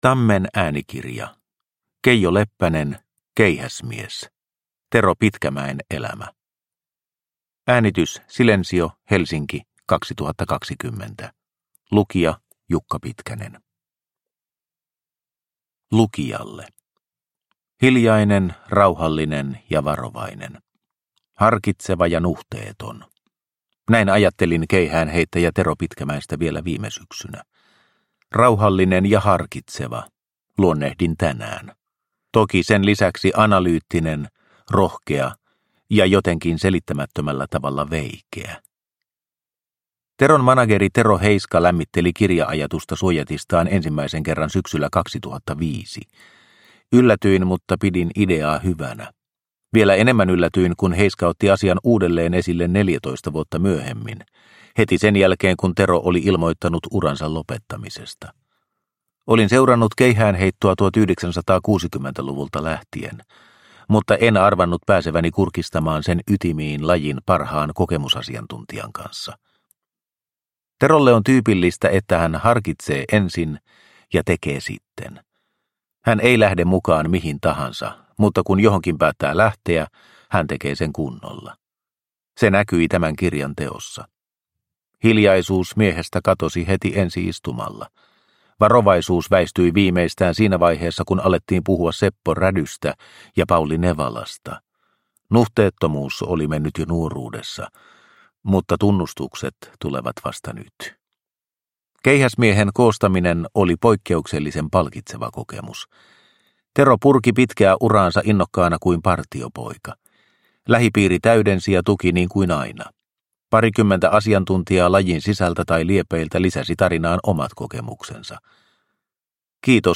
Keihäsmies – Ljudbok – Laddas ner